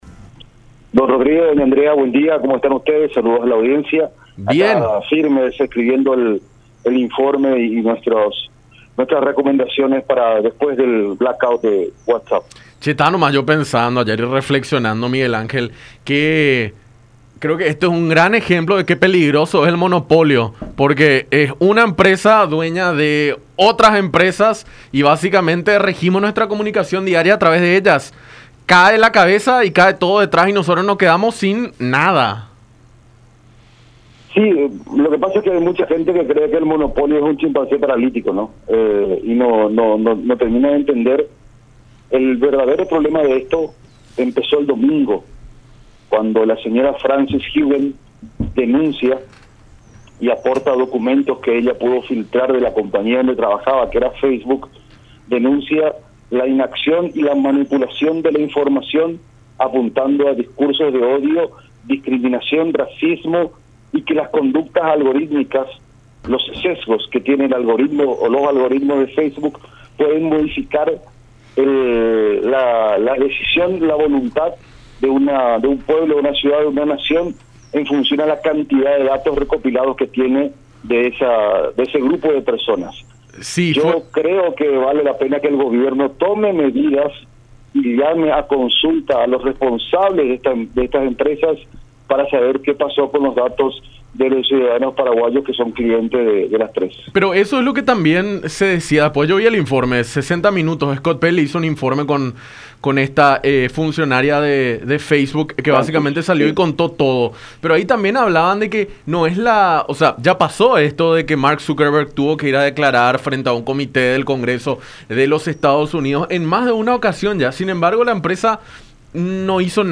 en conversación con Enfoque 800 a través de La Unión